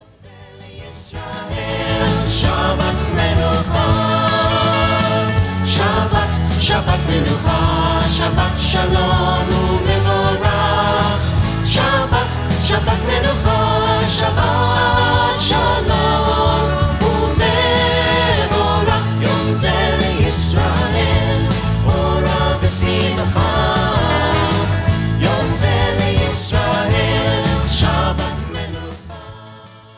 upbeat, singable music for worship and listening